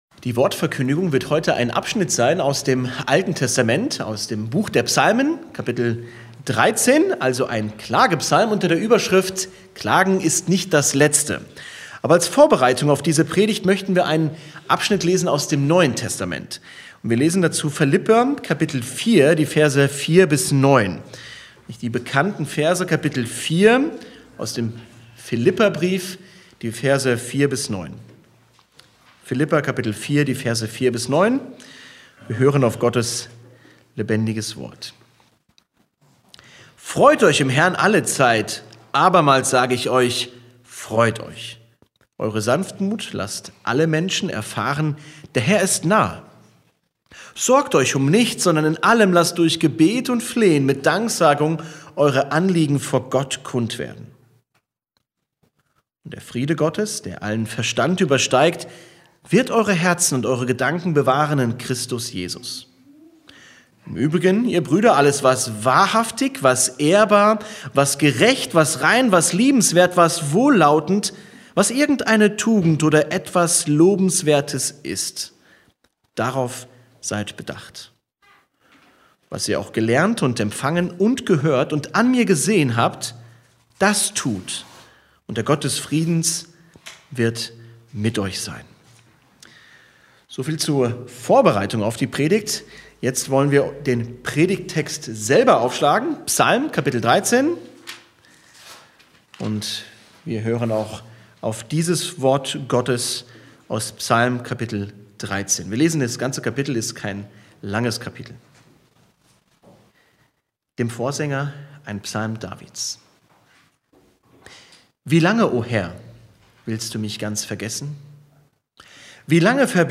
Die Predigt nimmt die verbreitete Scheu vieler Christen vor dem Klagen ernst: Wir sollen doch dankbar sein (Philipper 4,4–9 wird bewusst vorgelesen), und Klagen fühlt sich oft wie Undank oder Kleinglaube an.